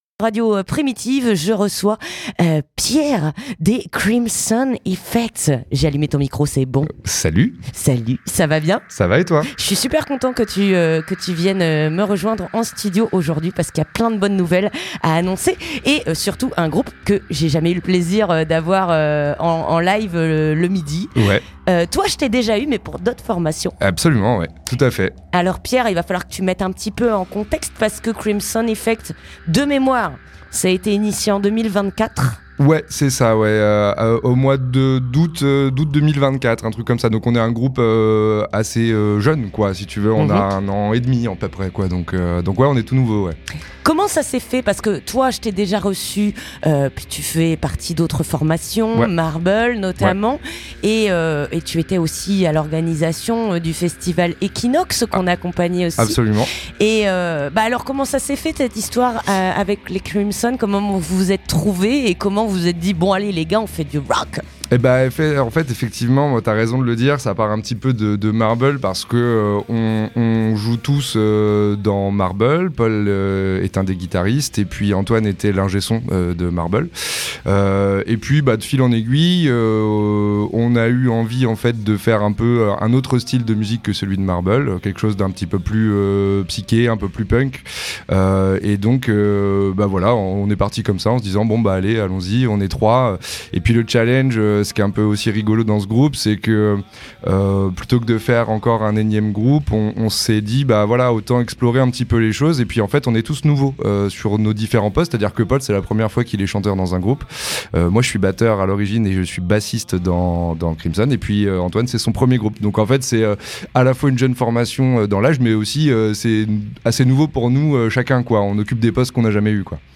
Monté en 2024, le power trio Rémois Crimson Effect fait son petit bout de chemin. Si bien qu'ils sortent un premier EP Dawn et son venus vous en parler sur Radio Primitive.